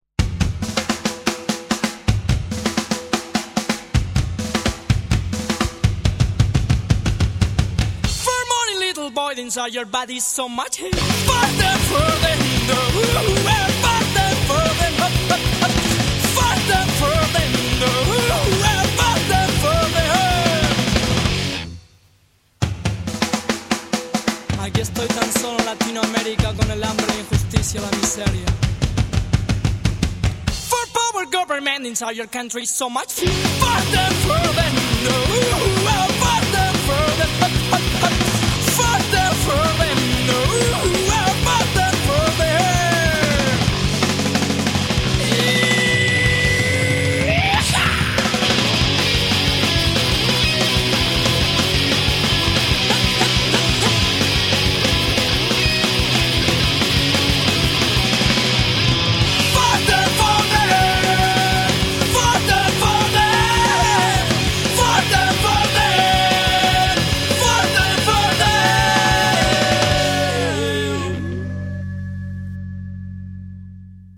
guitarra
bajo
bateria